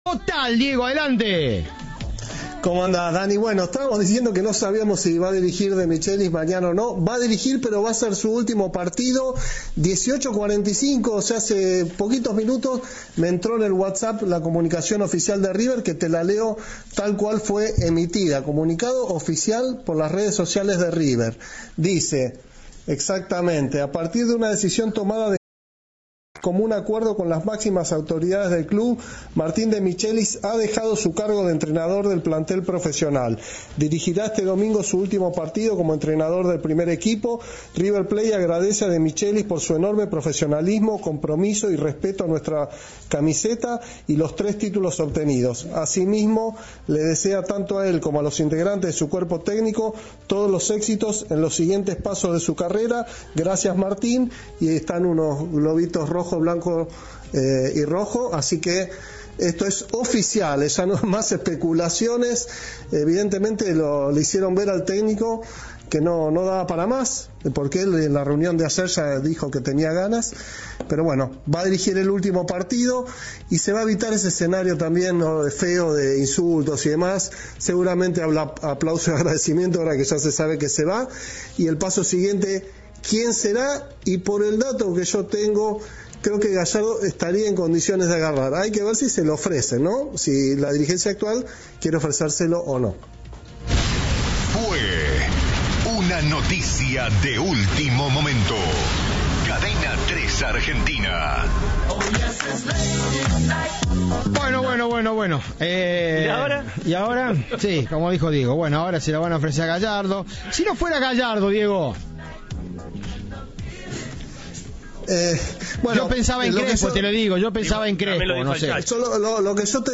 Informe